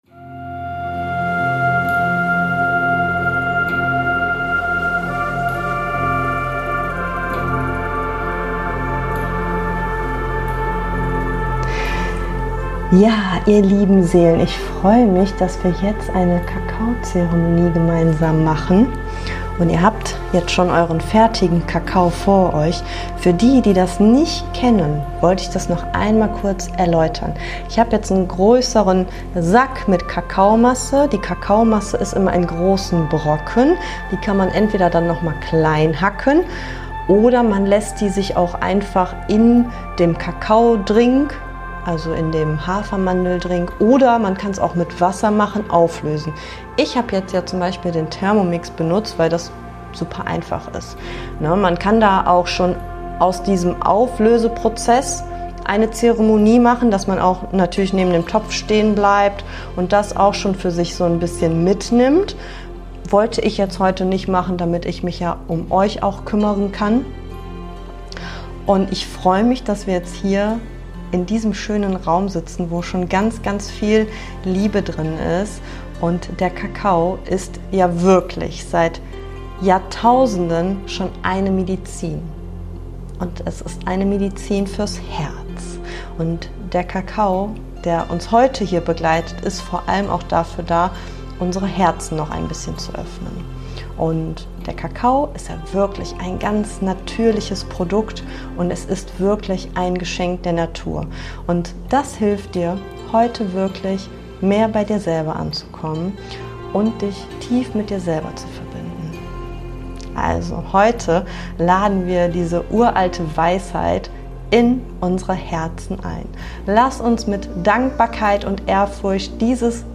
Während des "Selflove Festival" Coaching Workshops habe ich diese Kakao Zeremonie mitgefilmt.